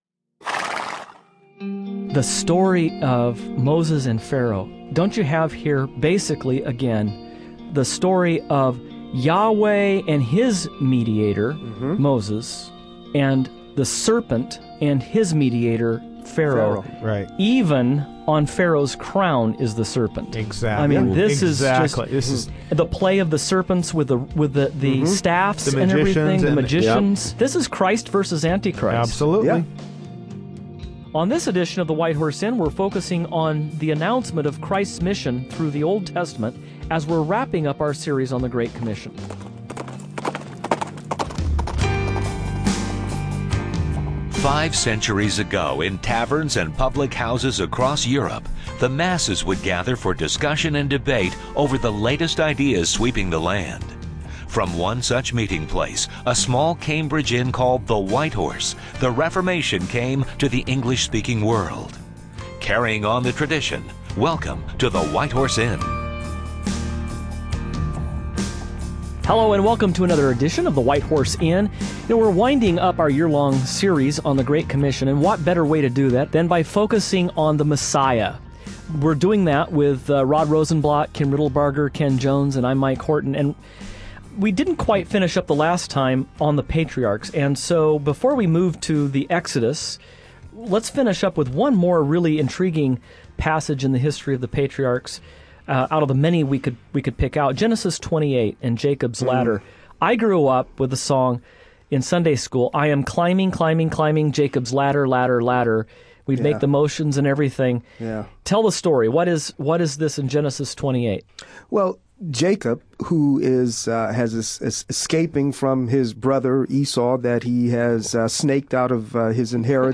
On this program the hosts are continuing to walk through the messianic story of redemption as it unfolds throughout the Old Testament. On this broadcast, the focus of the discussion primarily centers on the book of Exodus, and Moses' leading of the people of Israel to the promised land.